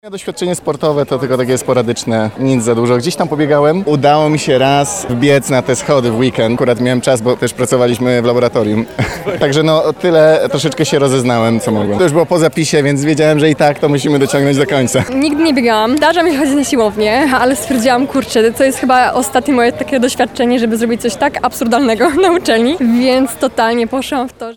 -mówią uczestnicy biegu.